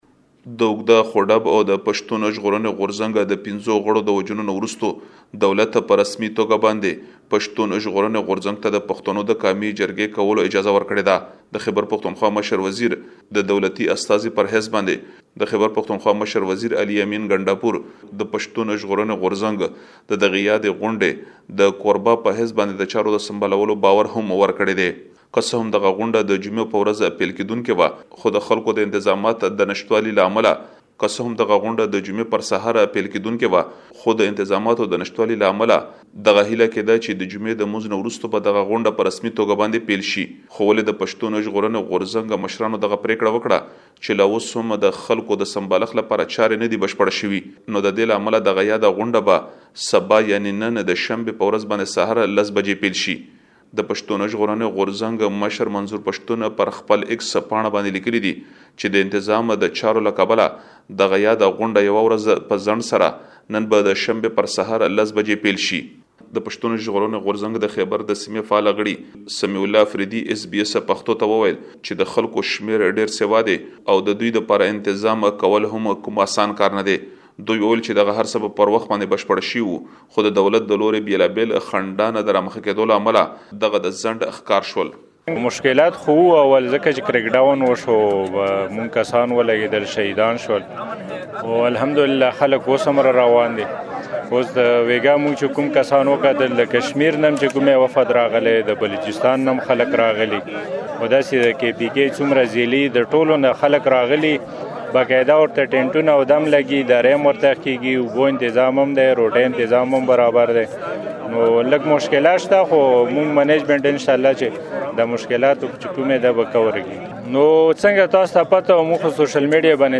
مهرباني وکړئ لا ډېر معلومات په رپوټ کې واوروئ.